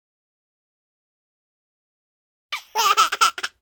tickle5.ogg